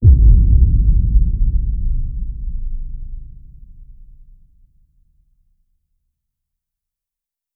lowboom.wav